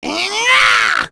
Lakrak-Vox_Attack6.wav